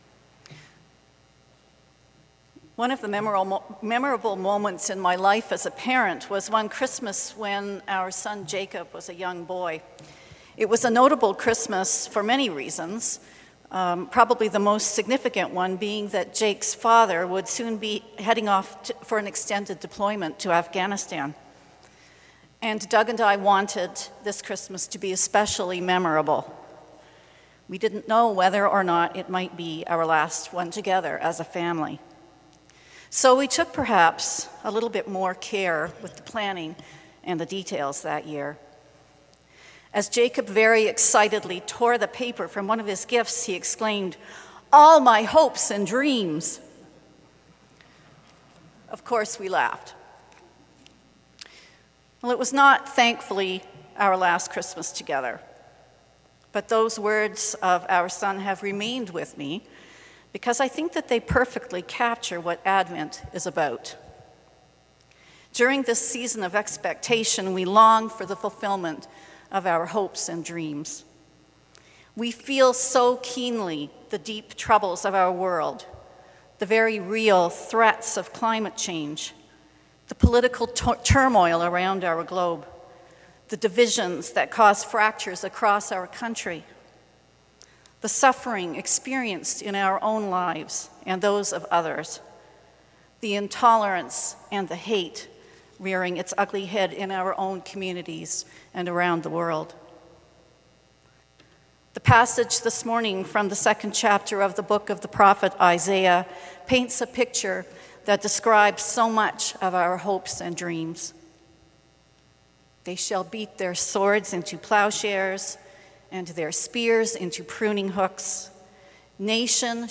Sermon: 9.15 a.m. service